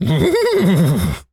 horse_neigh_calm_02.wav